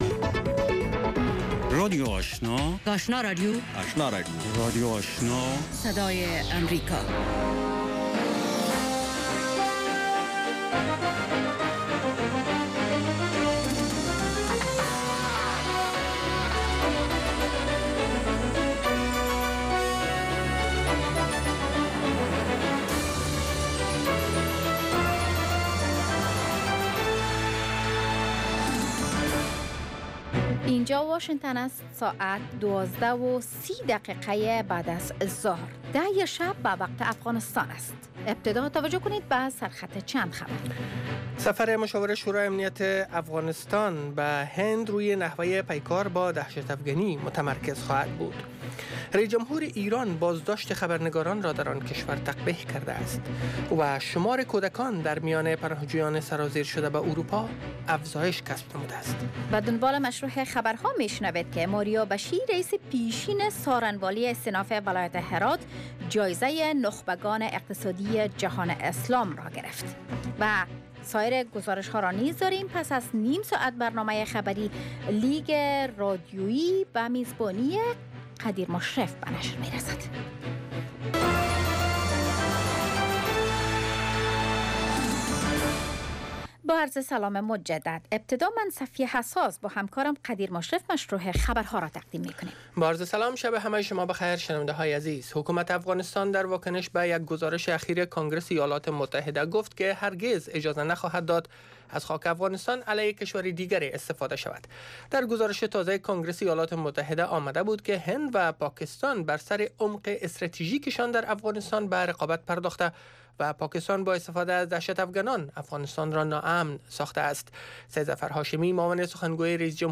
دومین برنامه خبری شب
برنامه گفت و شنود/خبری اتری - گفتمان مشترک شما با آگاهان، مقام ها و کارشناس ها.